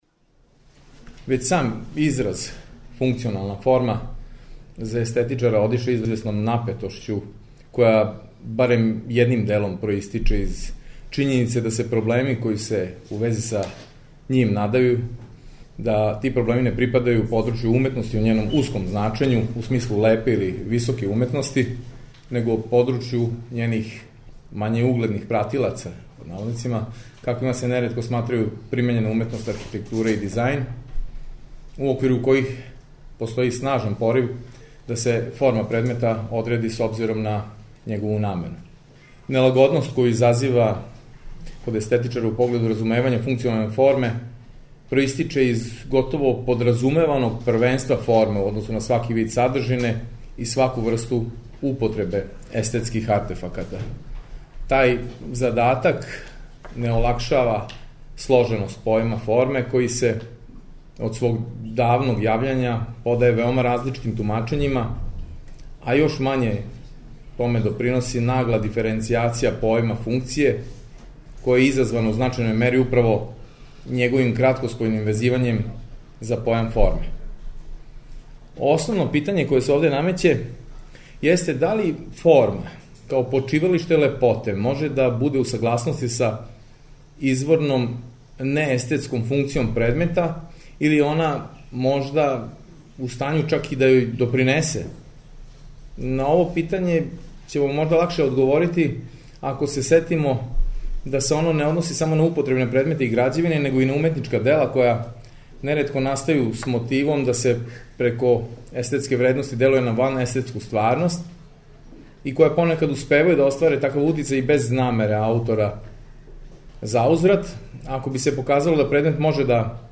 У циклусу ПРОБЛЕМ ФОРМЕ четвртком и петком ћемо емитовати снимке са истоименог научног скупа који је крајем прошле године организовало Естетичко друштво Србије из Београда.
Научни скупови